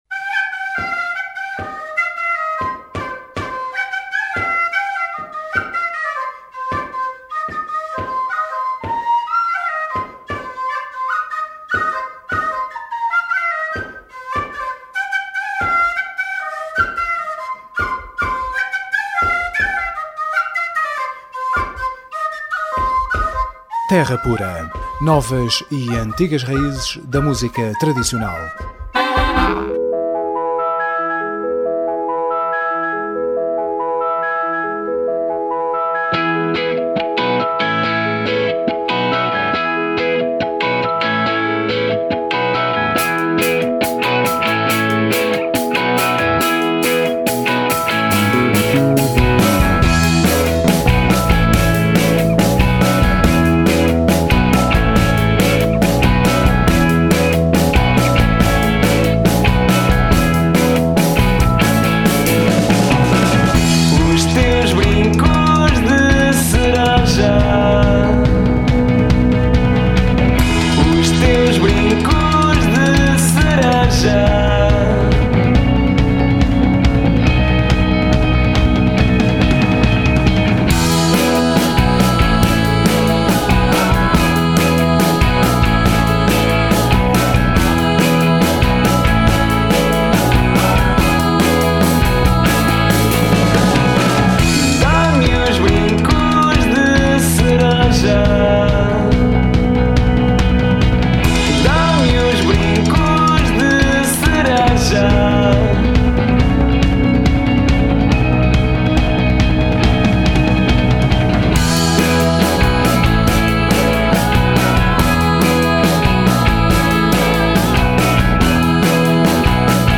Terra Pura 26DEZ11: Entrevista Os Capitães da Areia